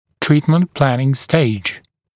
[‘triːtmənt plænɪŋ steɪʤ][‘три:тмэнт ‘плэнин стэйдж]этап планирования лечения